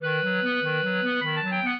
clarinet
minuet8-8.wav